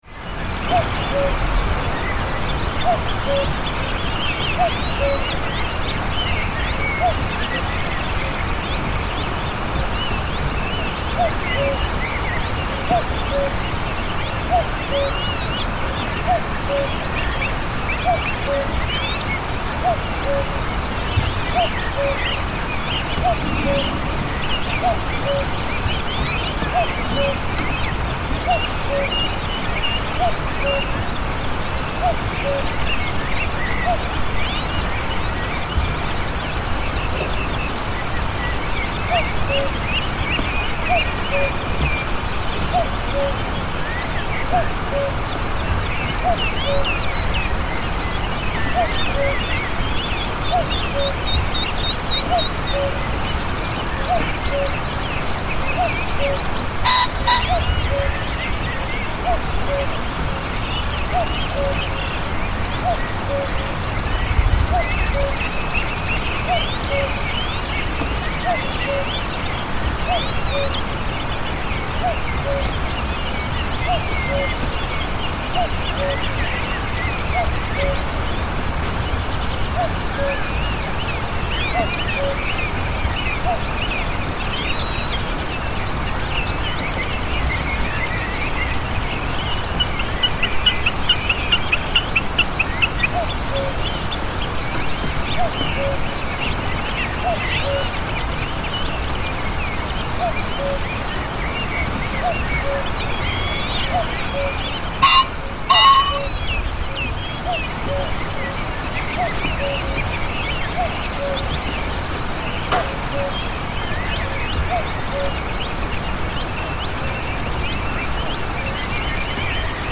Cuckoos at dawn
A soundscape of a particularly misty morning, recorded at 4.12am during late spring from the observing window of the Tomintoul Bird Hide. The hide sits on the edge of the highest village in the Highlands of Scotland.
The calls of cuckoos create an eerie presence that gives depths to the length of the surrounding valley. Along with the days awakening dawn chorus, loud insects chirping, a brash pheasant interrupts the scene as well as slight interior noise from the bird hide.